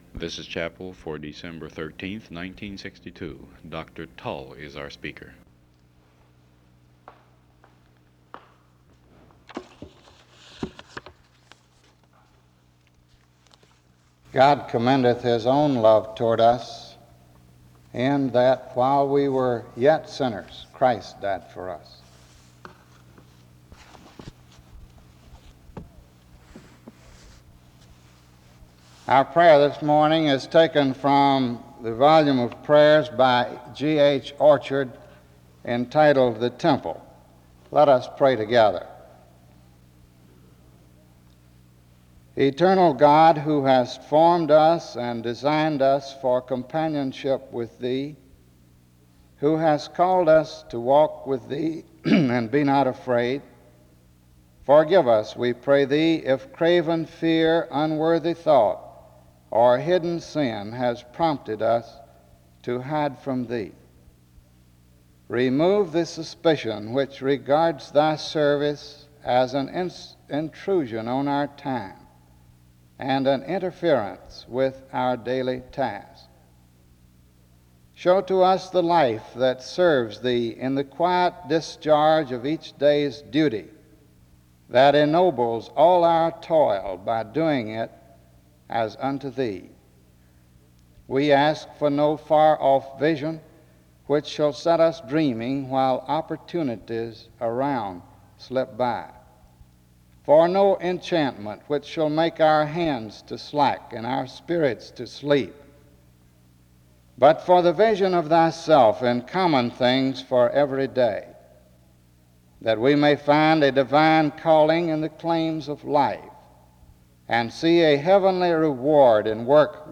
He encourages the students to be men of faith just like Abraham when he was called to sacrifice his son. There is closing music from 18:18-23:21.